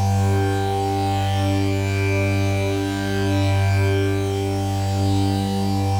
Index of /musicradar/dystopian-drone-samples/Non Tempo Loops
DD_LoopDrone4-G.wav